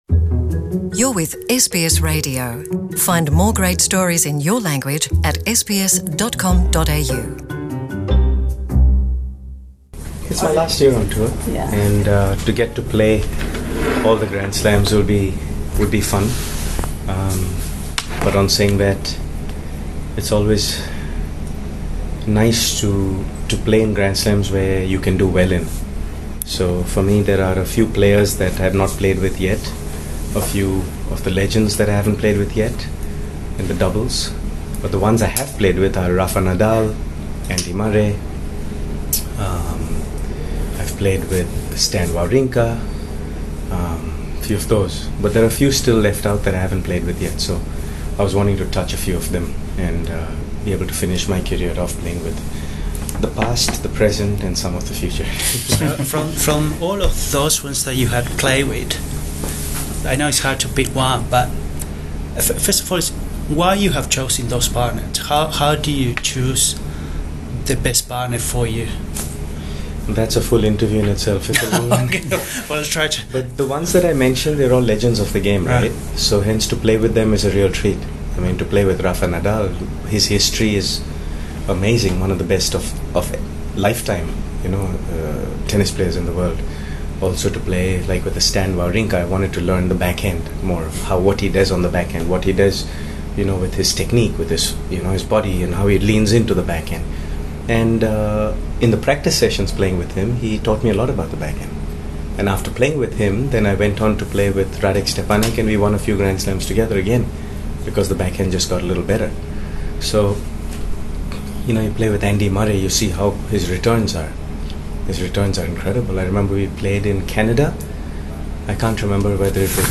The 46-year-old Indian tennis legend Leander Paes sits down with SBS Radio on the sidelines of Australia Open 2020 in Melbourne to talk about his illustrious career, how his parents influenced his life and his post-retirement plans.